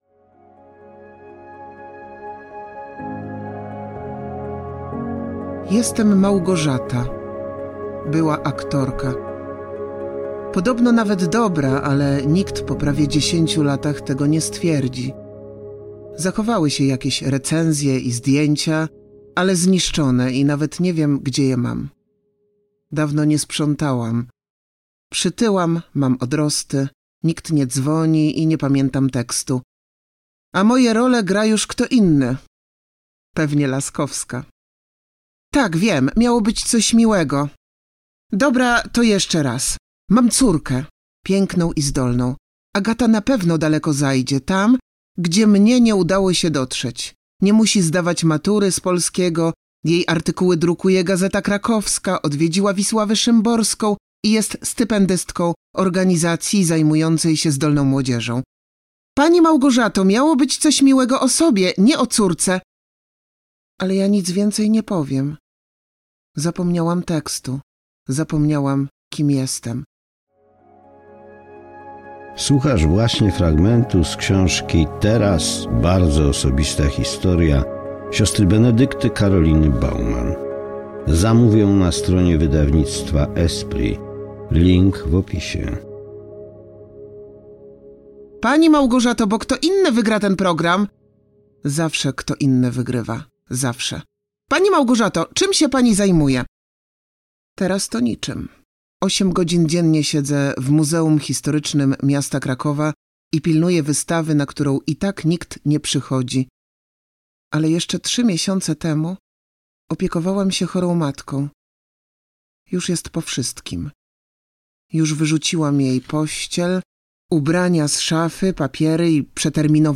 Darmowe audiobooki